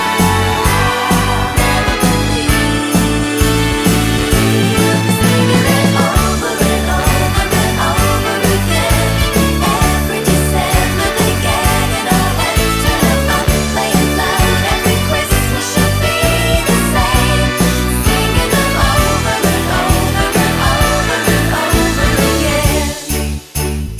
No Backing Vocals Christmas 3:24 Buy £1.50